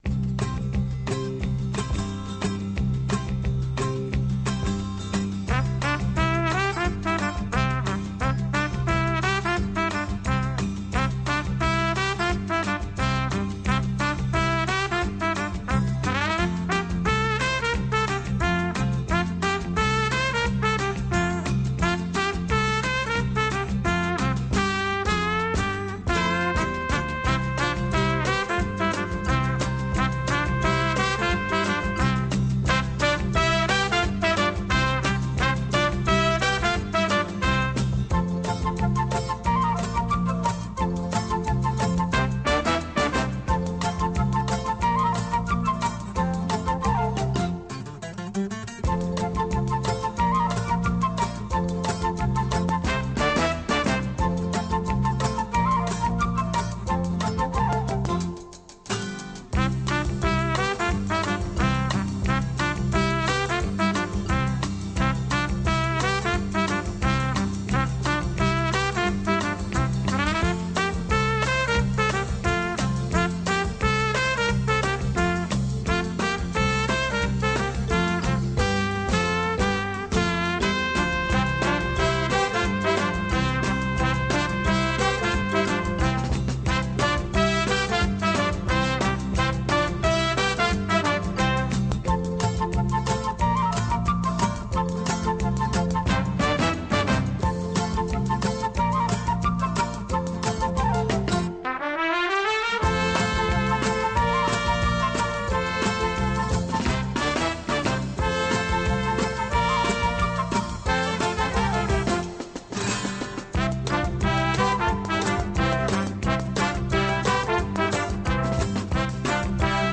Mexican Brass Style